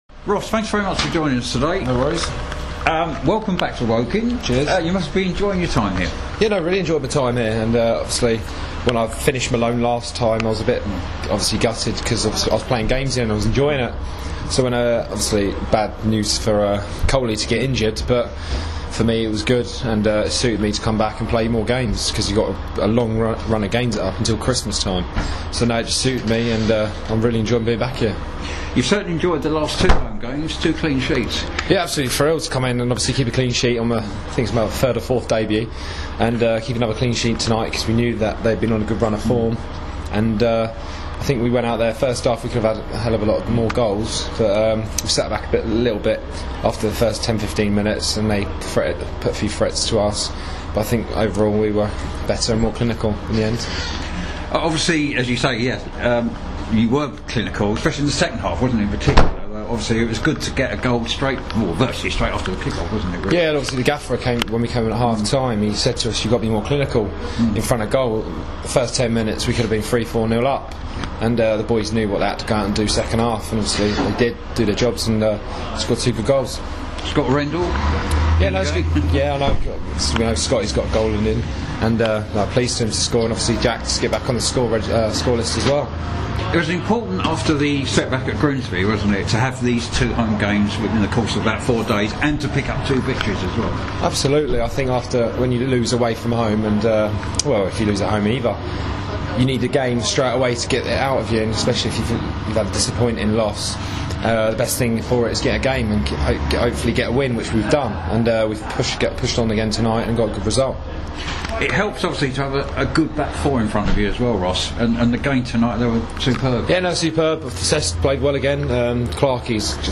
after Woking's2-0 win against Altrincham